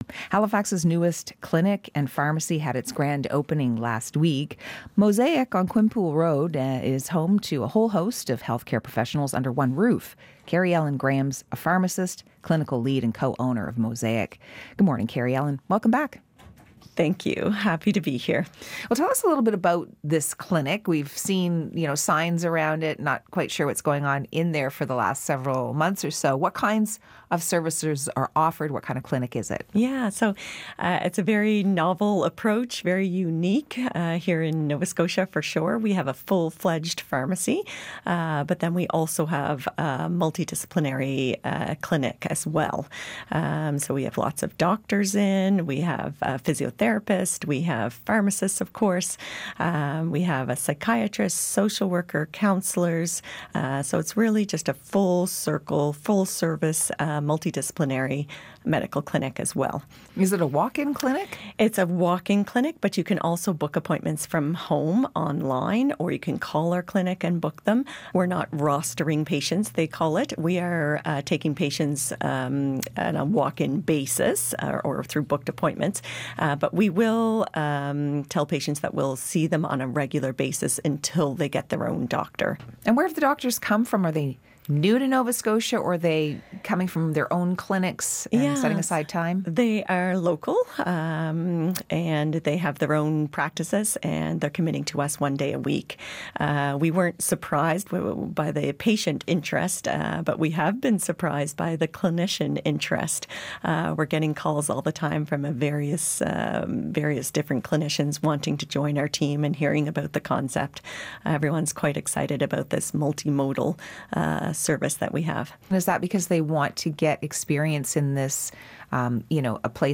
Information Morning Interview.wav